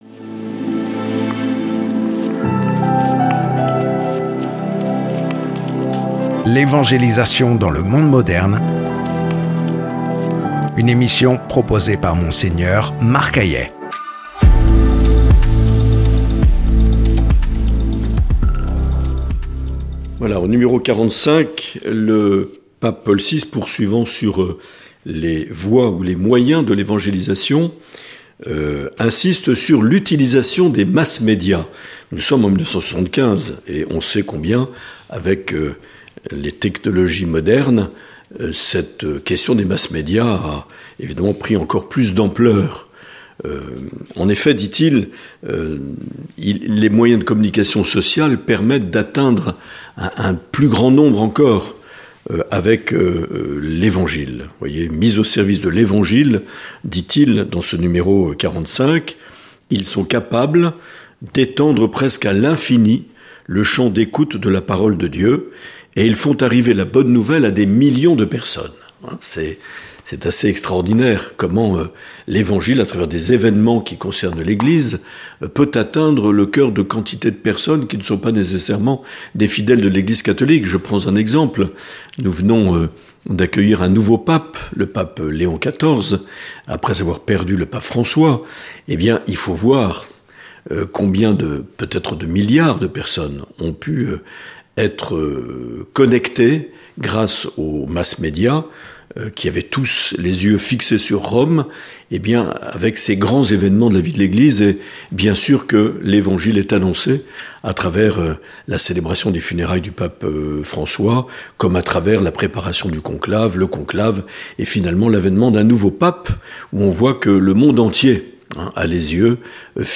Une émission présentée par
Monseigneur Marc Aillet
Présentateur(trice)